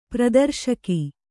♪ pradarśaki